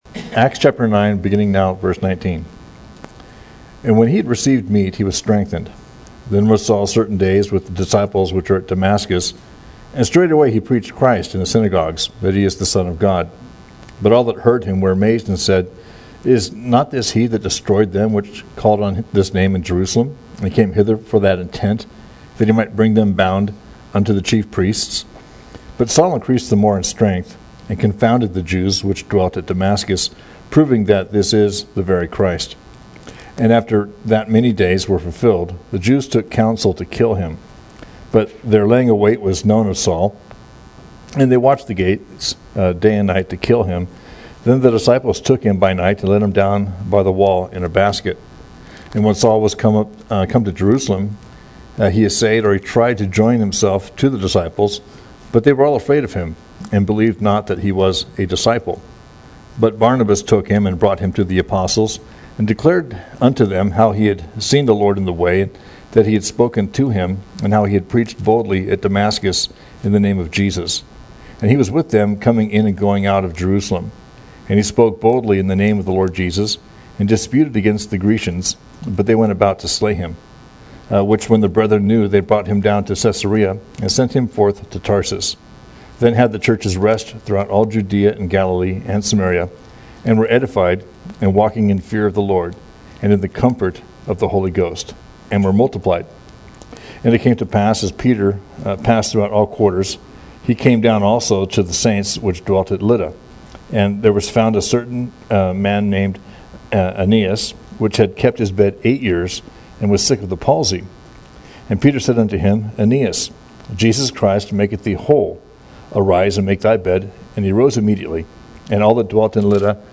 We livestream all of our services on FaceBook.